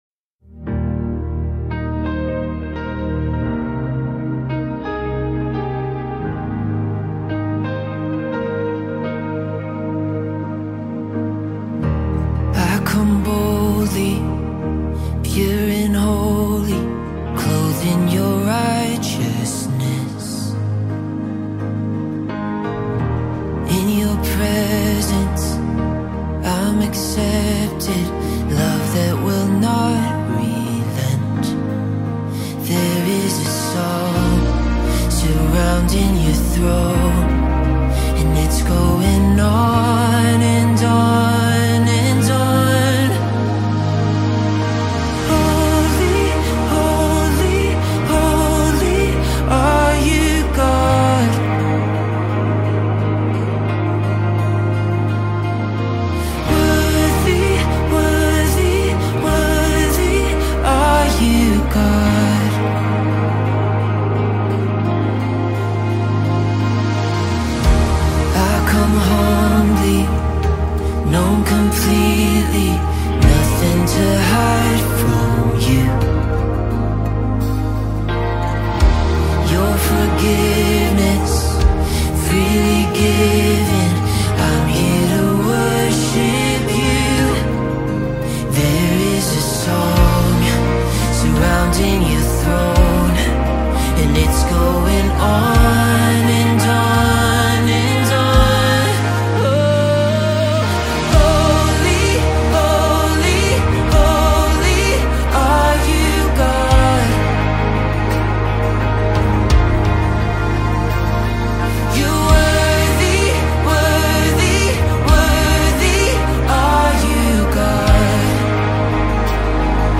Seattle-based worship team